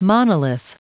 Prononciation du mot monolith en anglais (fichier audio)
Prononciation du mot : monolith